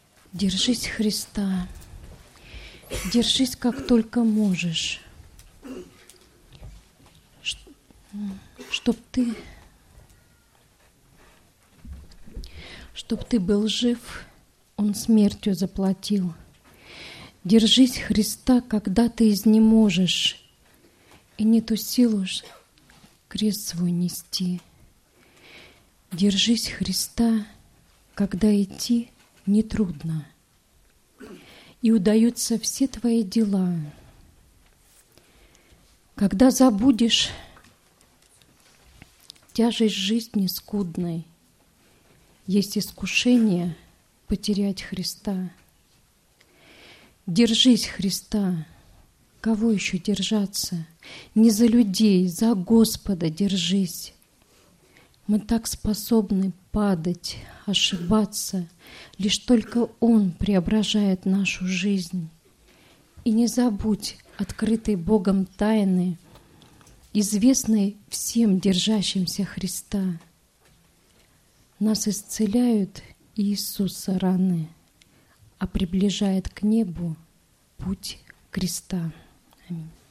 Богослужение 12.09.2010 mp3 видео фото
Стихотворение